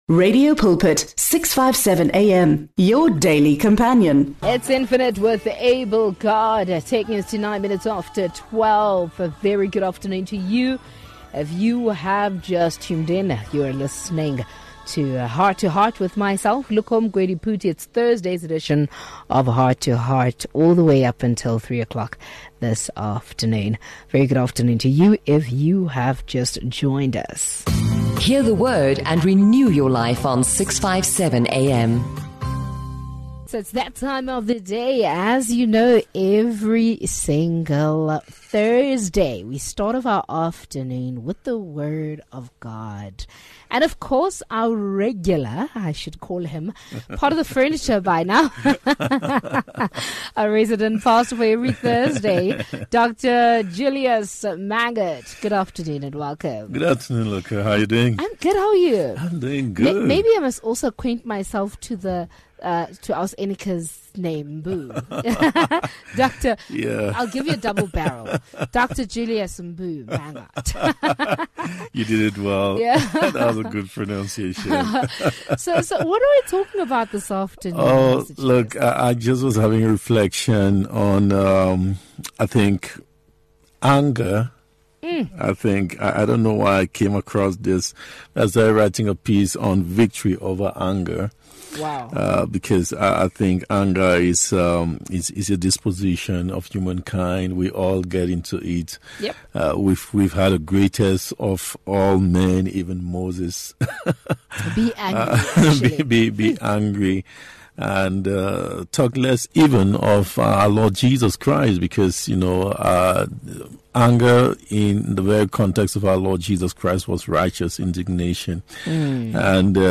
Heart to Heart is a magazine show on Radio Pulpit that brings you teachings, gospel music and advice.
To keep the content fresh, inspiring and from different perspectives, we have three presenters, each with their unique style.